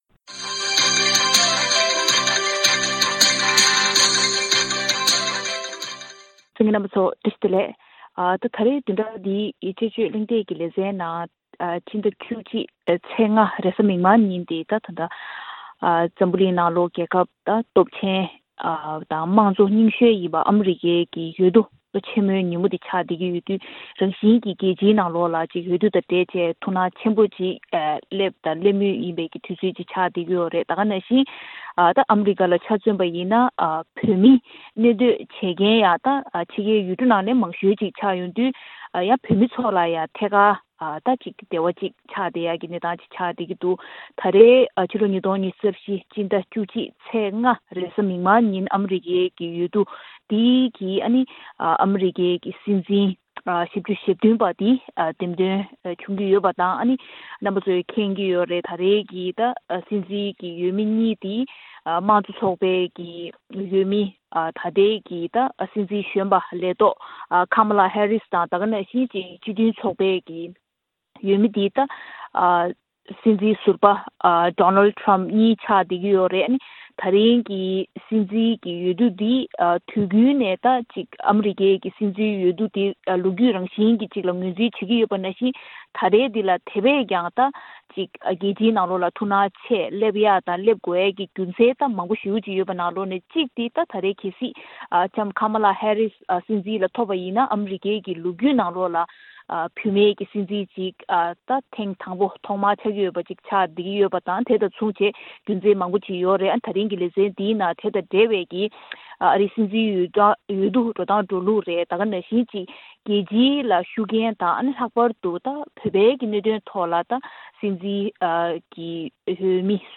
ཐེངས་འདིའི་དཔྱད་བརྗོད་གླེང་སྟེགས་ཀྱི་ལས་རིམ་ནང་།